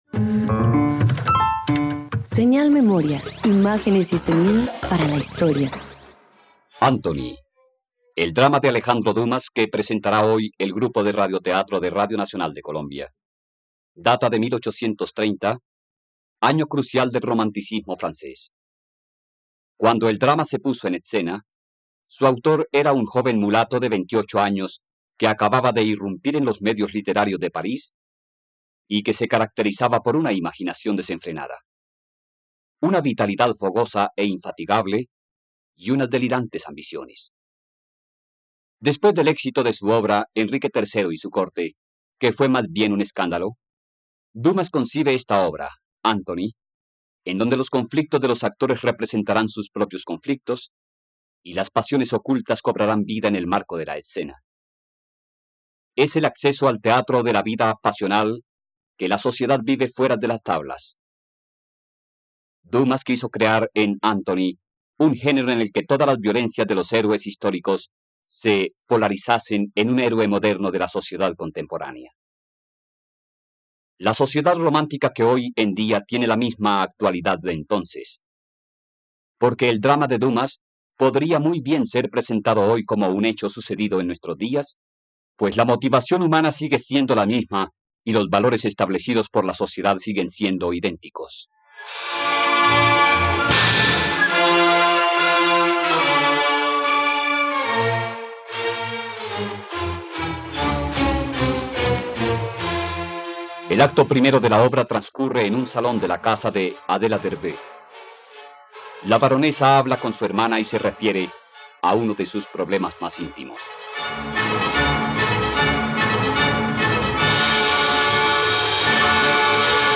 Anthony - Radioteatro dominical | RTVCPlay
..Radioteatro. Escucha la adaptación radiofónica de “Anthony” de Alejandro Dumas por la plataforma streaming RTVCPlay.